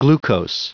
Prononciation du mot glucose en anglais (fichier audio)
Prononciation du mot : glucose